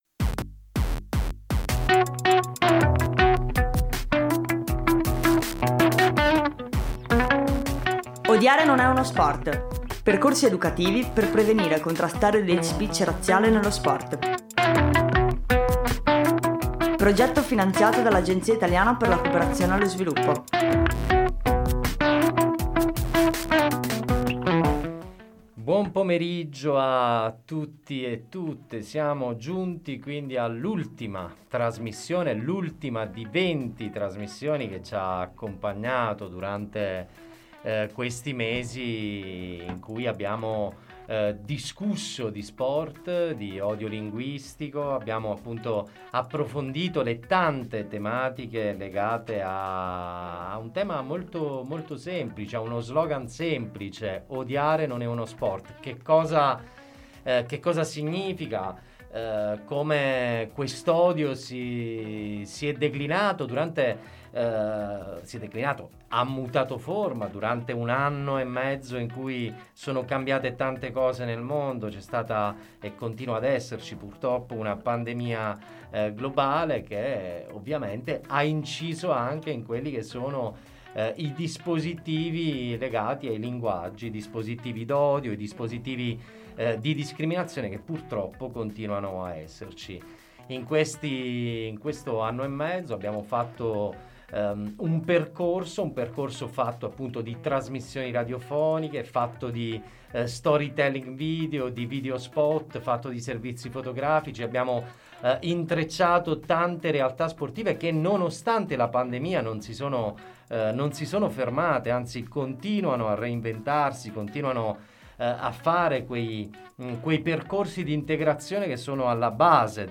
Siamo all’ultimo appuntamento con le trasmissioni di “Odiare non è uno sport”.